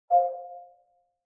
ping.ogg